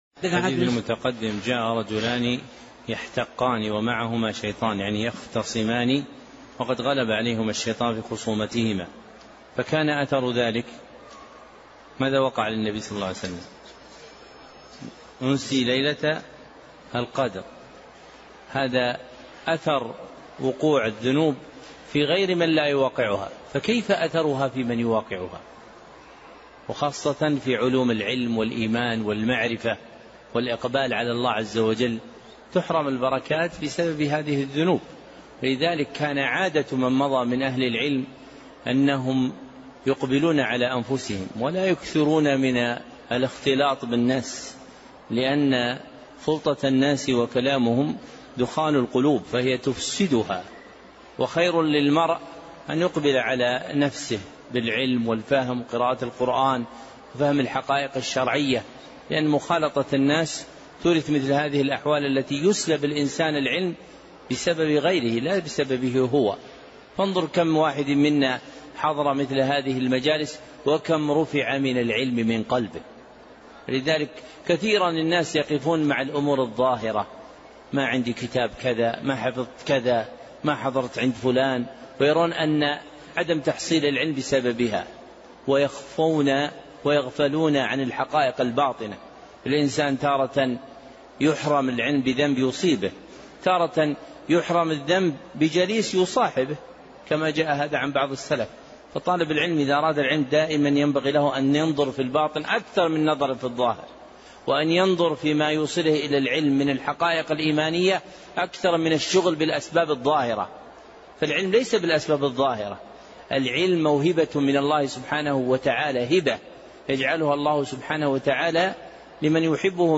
37 موعظة في الحال التي ينبغي أن يكون عليها طالب العلم مع قصة عظيمة لابن باز